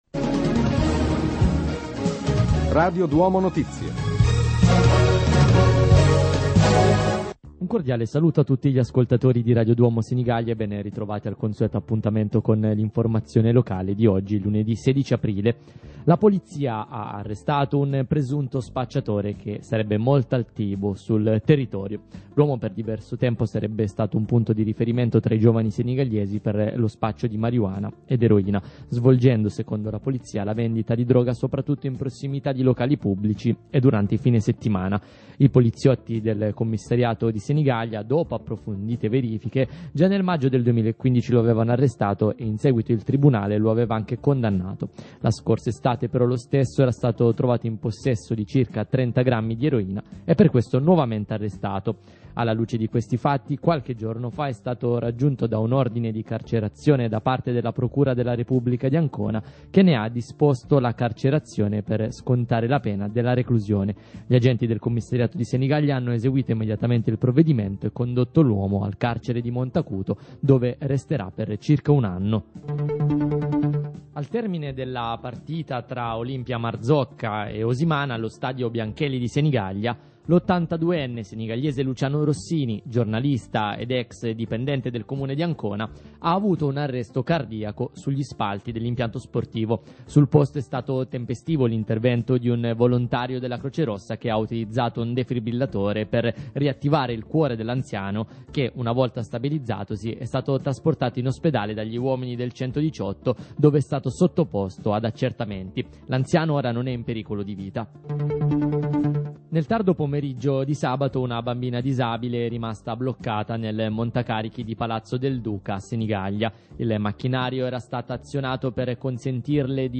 Radiogiornale Radio Duomo InBlu